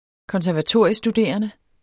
Udtale [ kʌnsæɐ̯vaˈtoˀɐ̯iə- ]